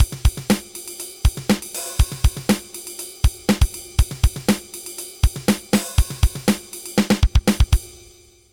Here’s a groove that will do that for me.
It’s in straight time, but cut me some slack — I haven’t touched a stick in six months.
The first half of each bar is the same throughout the groove; this establishes a structure from which we’ll attempt to hang music. The second half of each bar varies, injecting some rhythmic tension and interest, and maybe some melody.